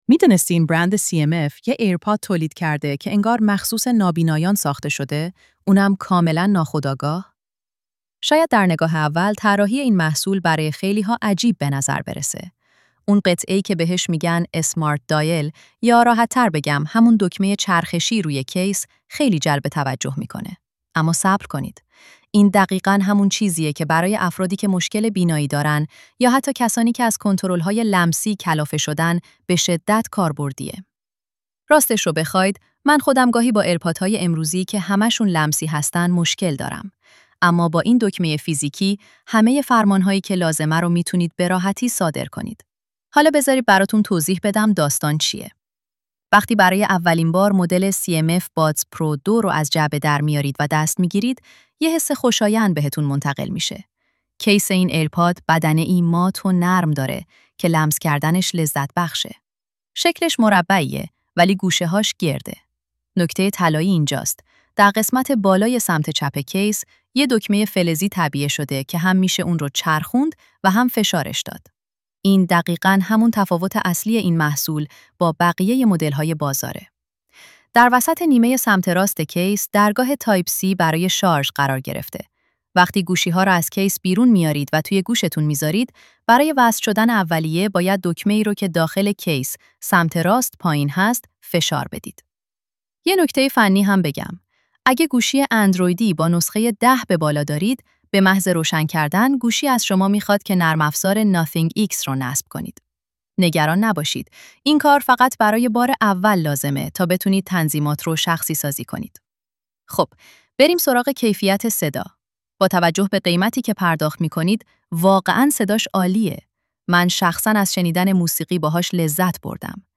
گوینده هوش مصنوعی